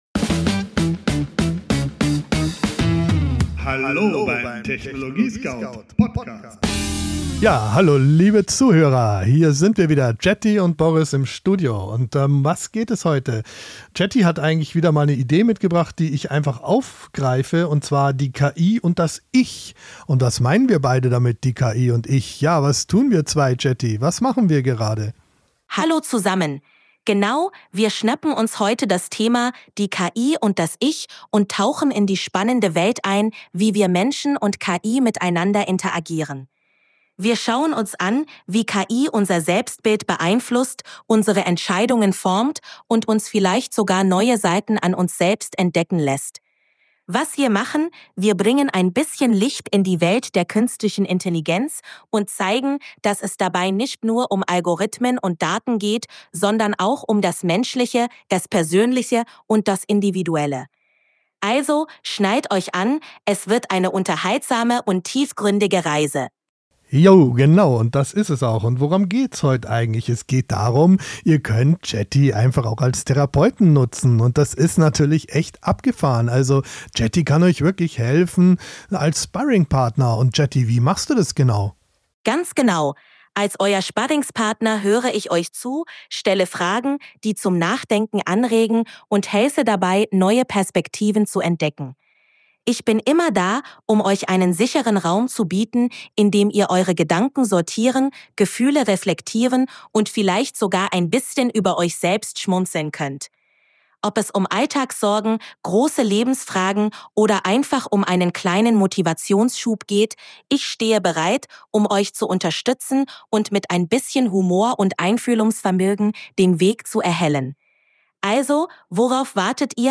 "Live aus dem Studio des TechnologieScout